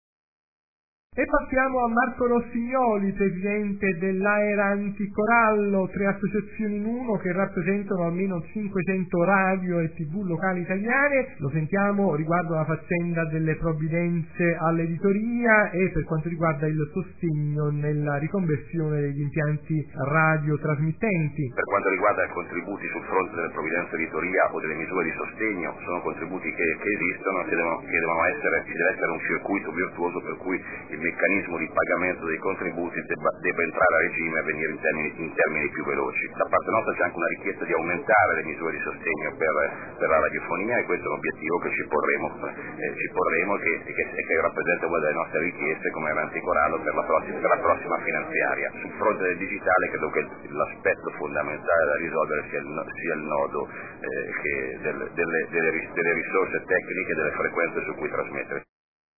Oggi ci stiamo occupando del passaggio della televisione al digitale terrestre, con una serie di interviste raccolte nei giorni  scorsi ad u convegno itinerante promosso  dell’’Istituto per lo studio dell’Innovazione nei media e per la Multimedialità ISIMM, la Fondazione Bordoni e i Corecom di Lazio, Campania e Sicilia.